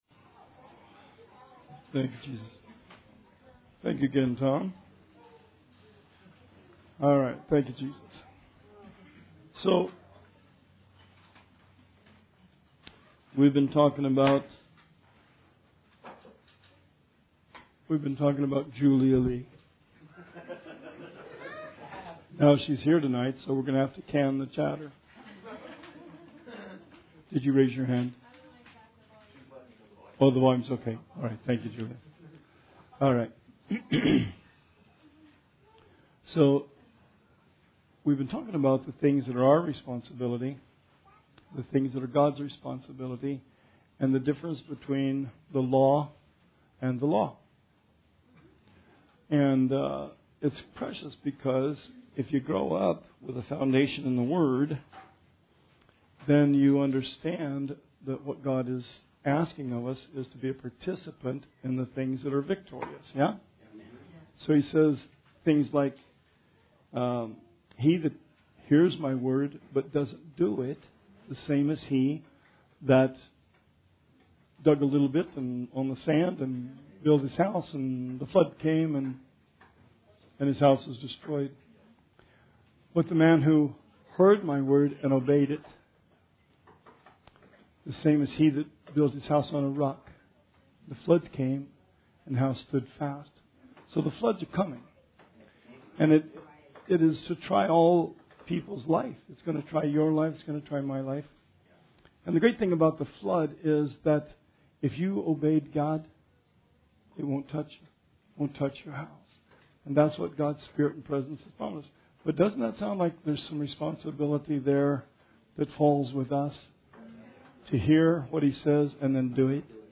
Bible Study 6/19/19